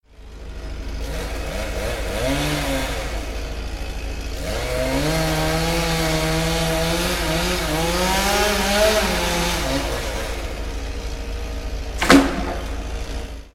دانلود آهنگ اره برقی از افکت صوتی اشیاء
جلوه های صوتی
دانلود صدای اره برقی از ساعد نیوز با لینک مستقیم و کیفیت بالا